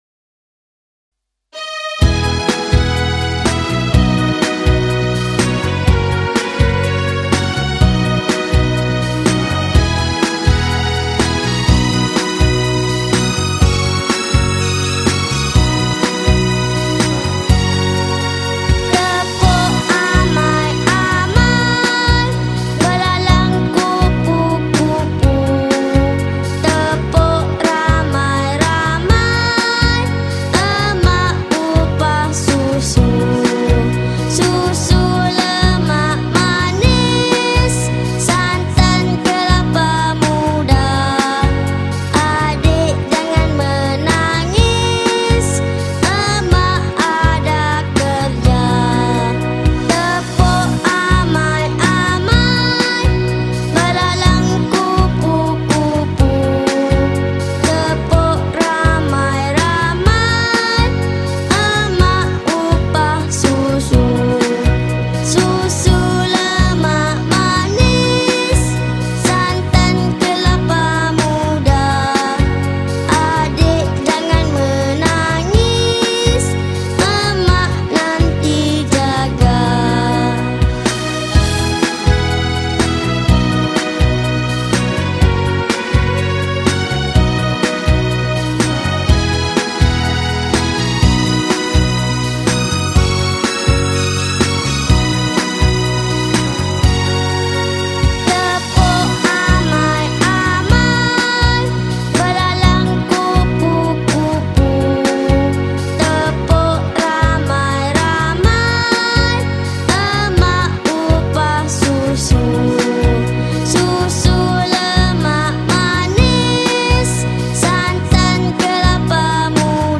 Children Song , Lagu Kanak-Kanak